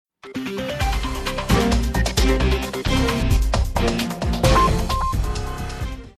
Vous allez rajouter au fichier précédent d’autres éléments : une musique de générique de début et de fin.